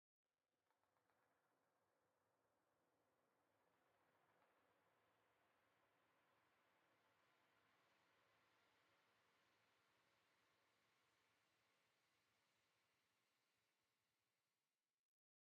pale_hanging_moss3.ogg